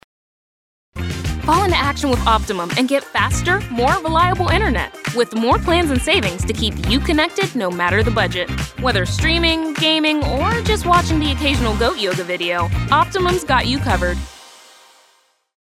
Commercial & problem Corporate Work
Optimum — Upbeat
Commercial-Optimum.mp3